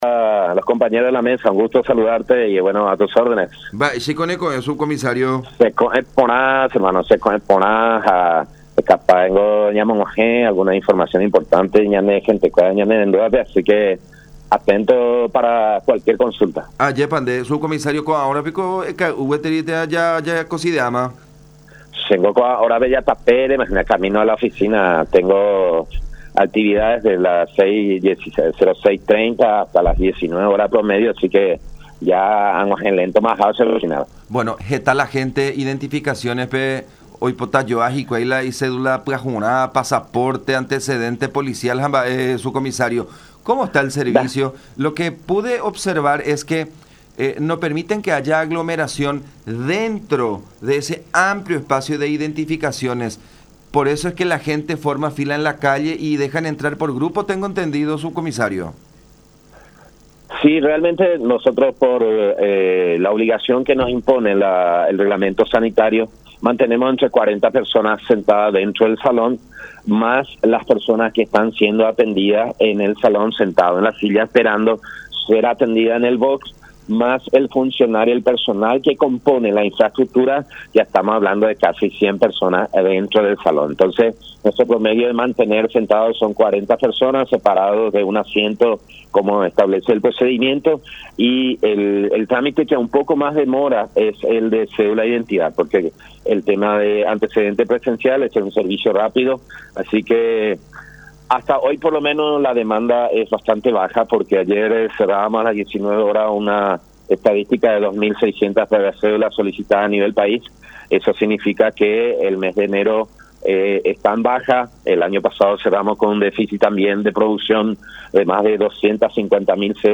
en conversación con La Unión